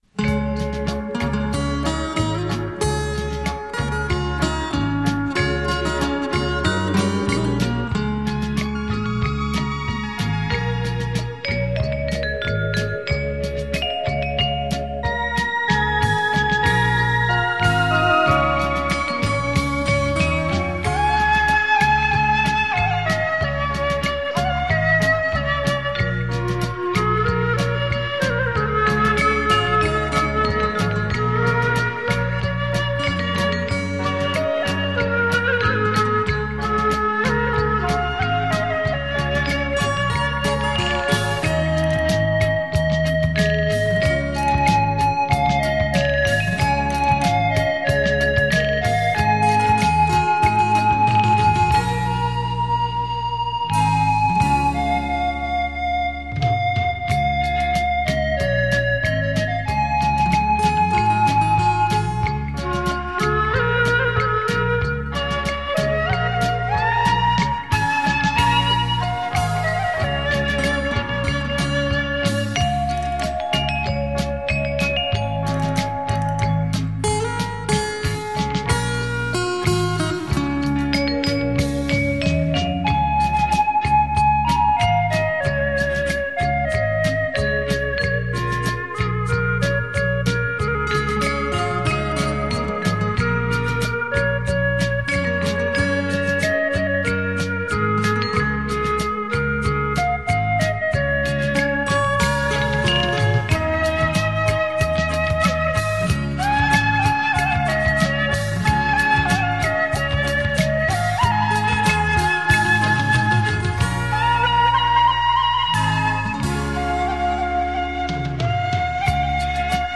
这是一组根据流行于台湾地区歌谣改编的洞箫曲，由于采用电声乐队
伴奏，使得专辑充满着现代的乡土气息。